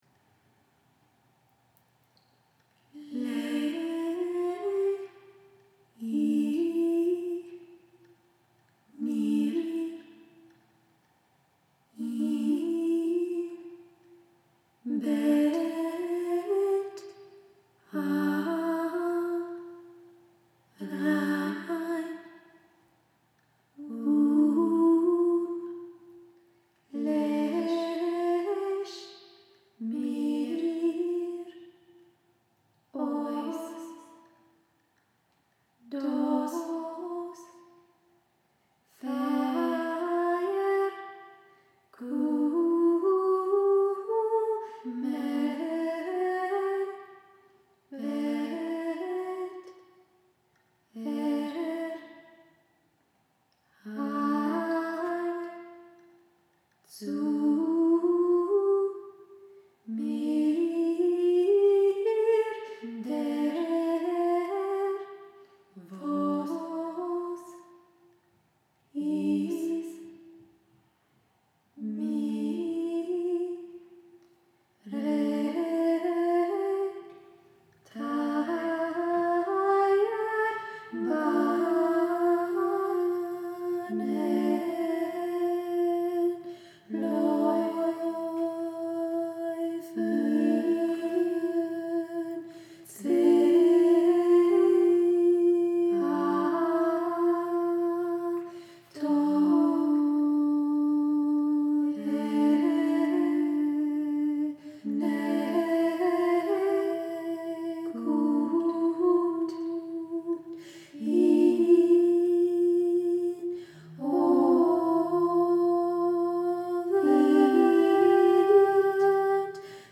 all voices